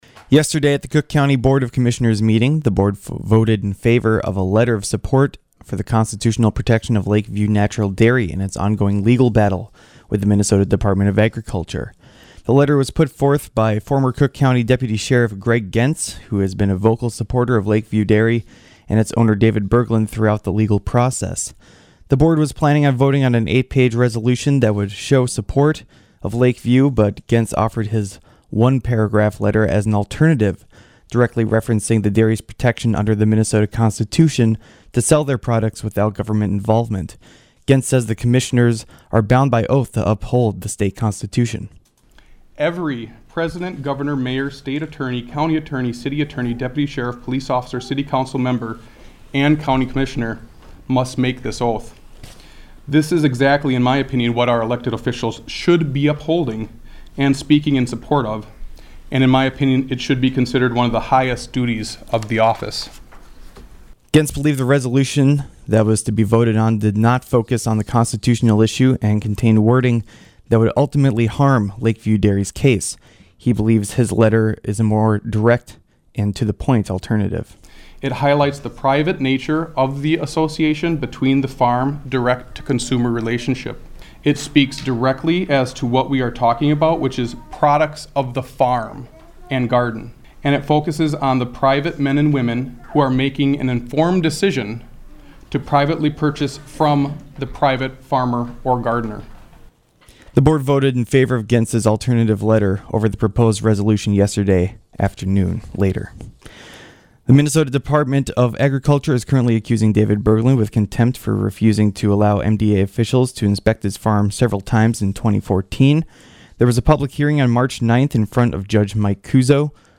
NEWS_Dairy.mp3